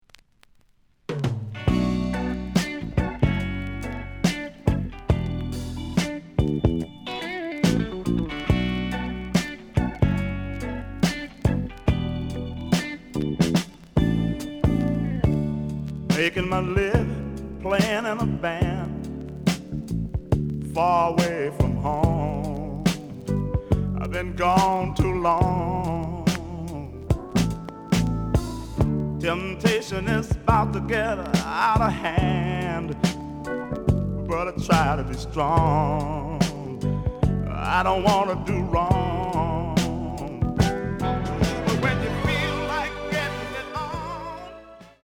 The audio sample is recorded from the actual item.
●Genre: Soul, 70's Soul
Edge warp. But doesn't affect playing. Plays good.)